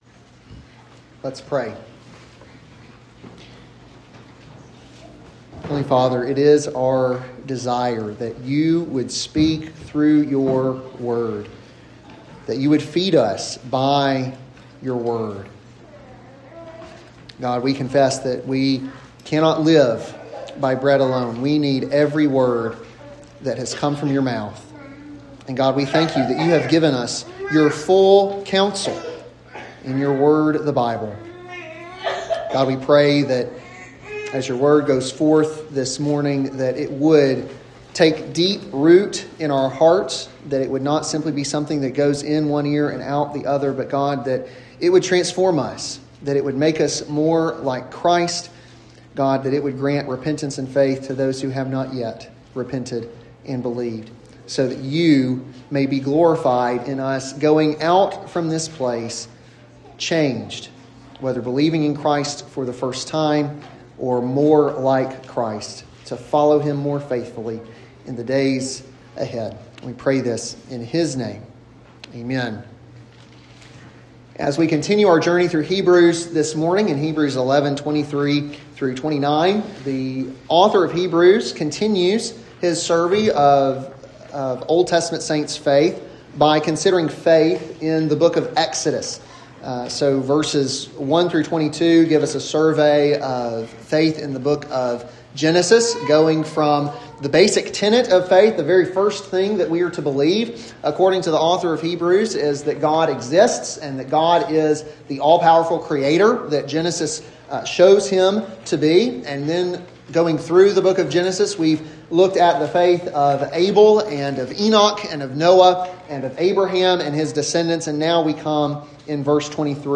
an expository sermon on Hebrews 11:23-29